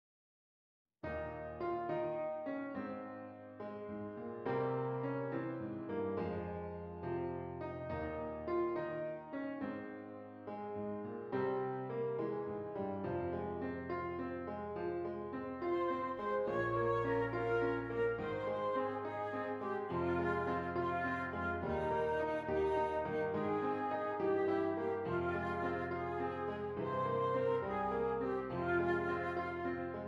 Flute Solo with Piano Accompaniment
Does Not Contain Lyrics
F Minor
Andante